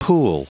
Prononciation du mot pool en anglais (fichier audio)
Prononciation du mot : pool